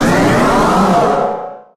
Cri de Méga-Tyranocif dans Pokémon HOME.
Cri_0248_Méga_HOME.ogg